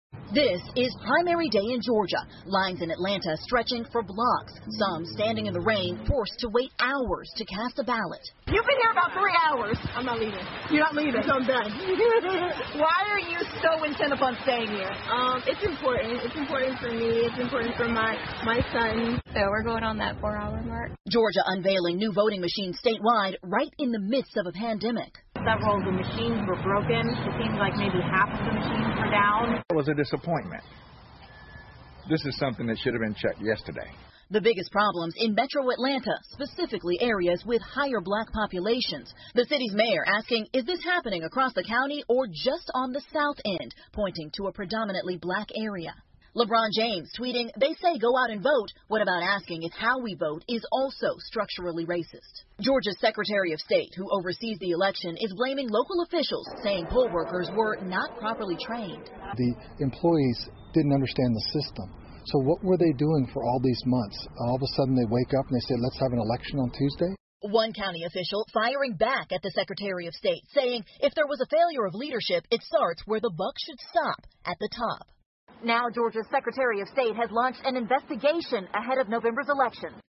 NBC晚间新闻 亚特兰大投票机出问题 听力文件下载—在线英语听力室